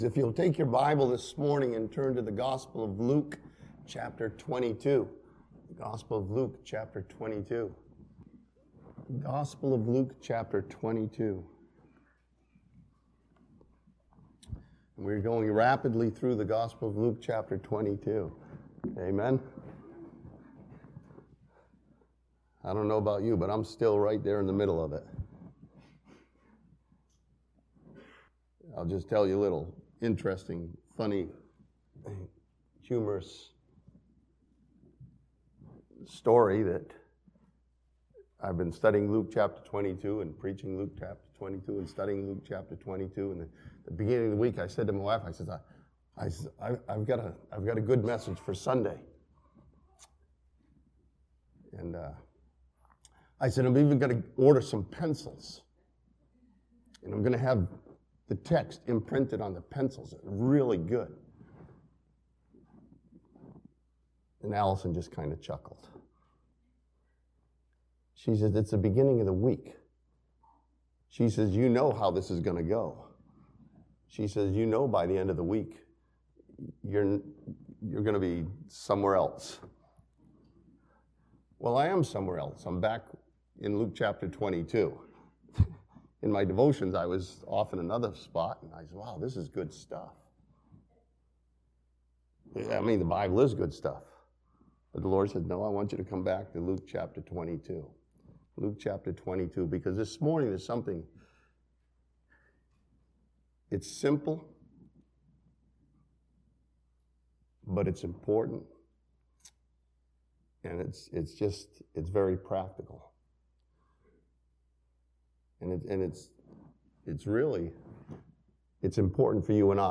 This sermon from Luke chapter 22 challenges believers to trust what God has said over what they think or feel.